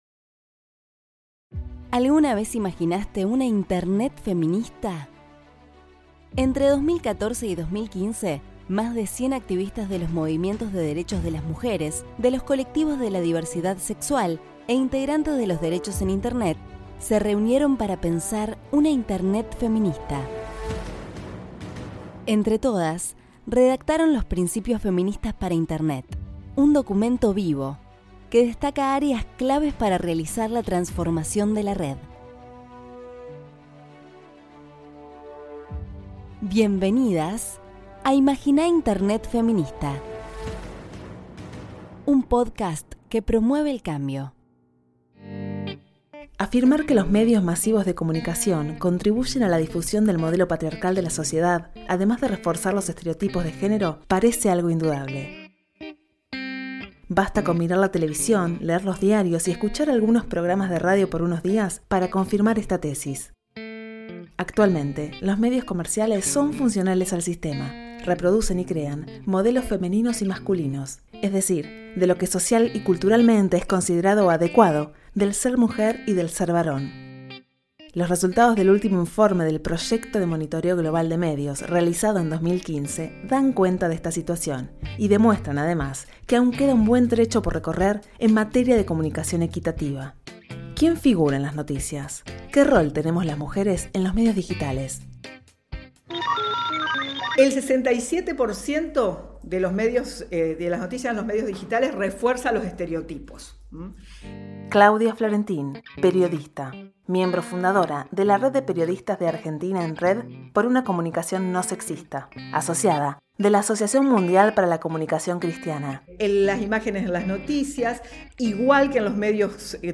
Locución
Entrevistas